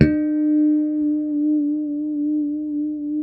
E4 PICKHRM2C.wav